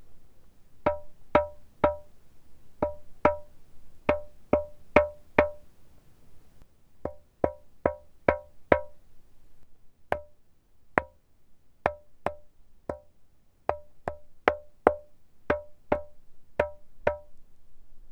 Type: Klangstein
Lyden i selve klokkesteinen er ikke spesielt sterk eller klar, og kan beskrives som dyp og metallisk.
Det er to frekvenser i dette området, avhengig av hvor man slår.
Andorfjellet-lyd.wav